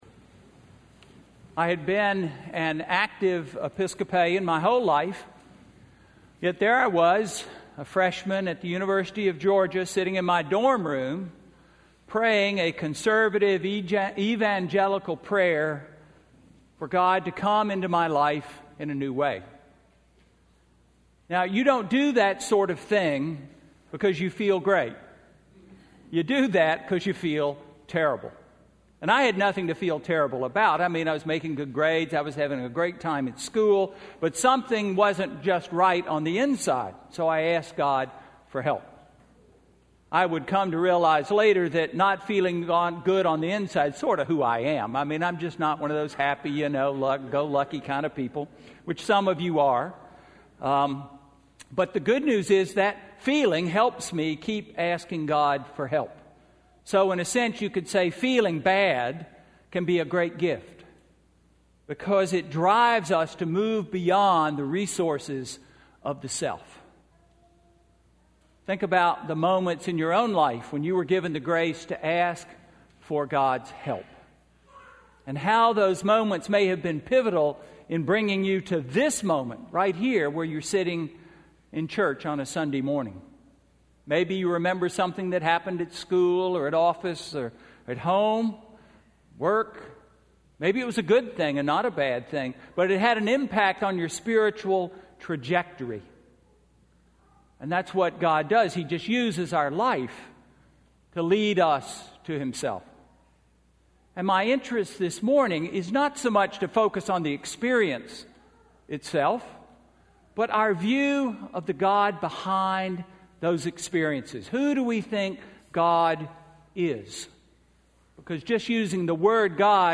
Sermon–January 12, 2014